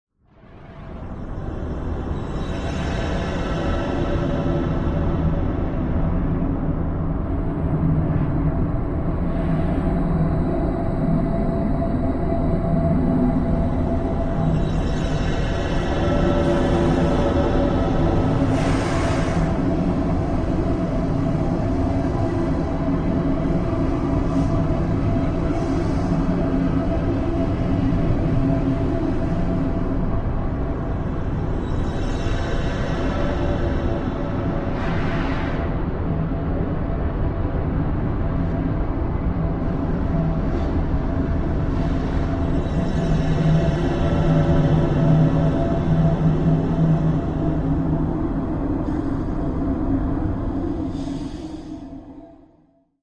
Supernova heavy engine rumble with cycling ambient shimmering effects